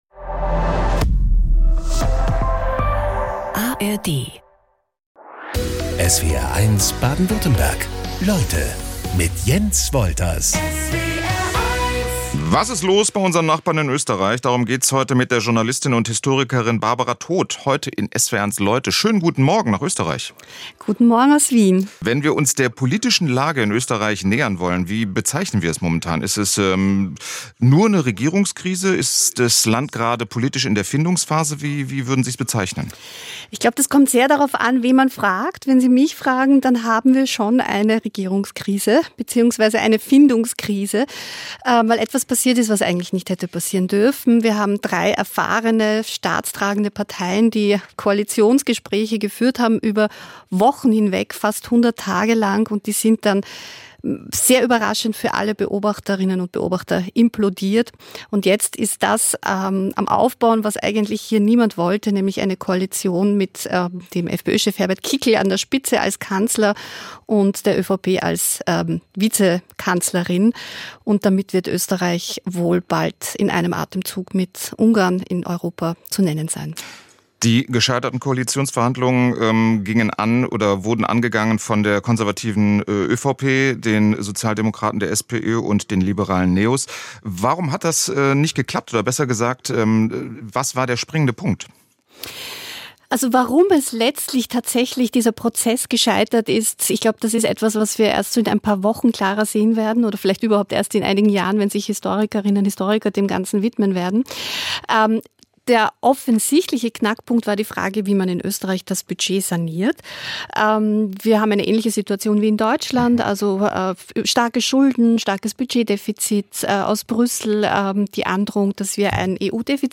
Jeden Tag talken unsere SWR1 Leute-Moderator:innen in Baden-Württemberg und Rheinland-Pfalz mit interessanten und spannenden Gästen im Studio.